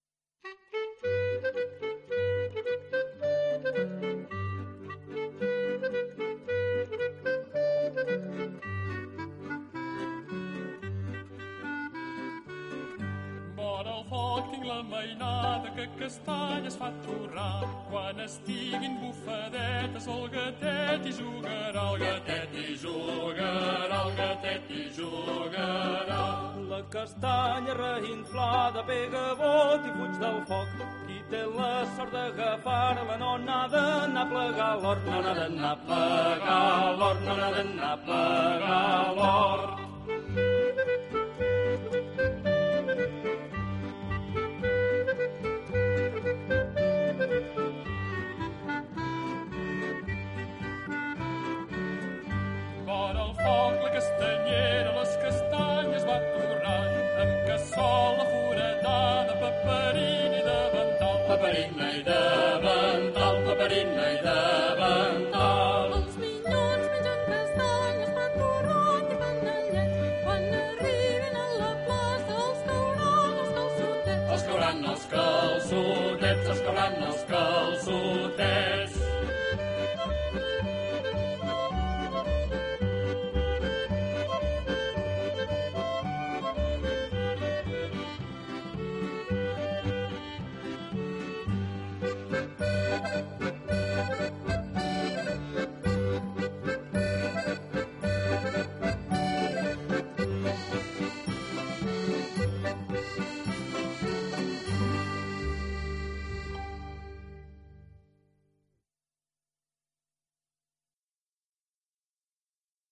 Cançons i danses de la tardor